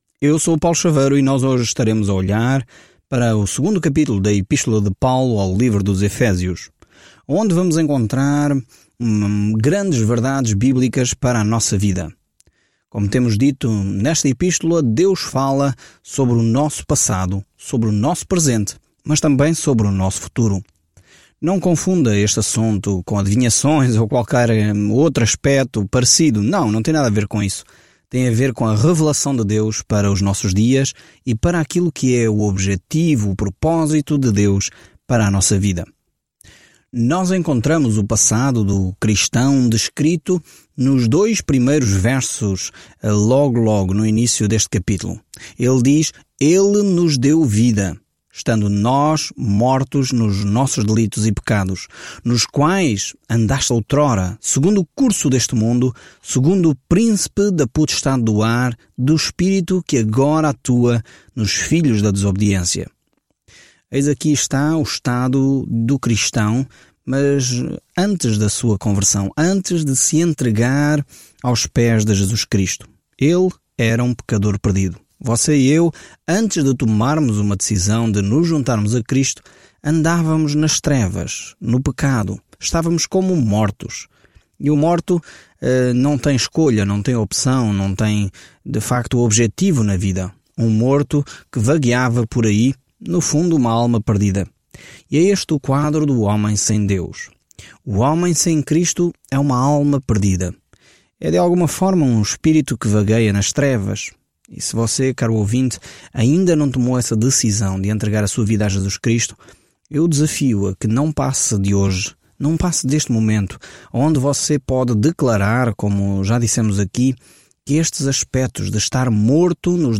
Escritura EFÉSIOS 2:3-7 Dia 10 Iniciar este Plano Dia 12 Sobre este plano Das belas alturas do que Deus deseja para seus filhos, a carta aos Efésios explica como andar na graça, na paz e no amor de Deus. Viaje diariamente por Efésios enquanto ouve o estudo em áudio e lê versículos selecionados da palavra de Deus.